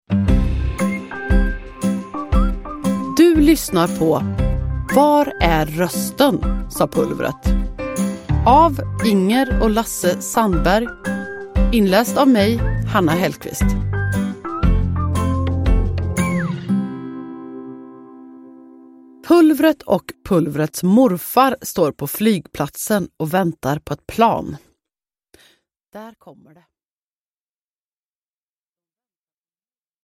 Var är rösten, sa Pulvret – Ljudbok – Laddas ner
Lyssna på Inger och Lasse Sandbergs klassiska böcker om Pulvret inlästa av Hanna Hellquist.
Uppläsare: Hanna Hellquist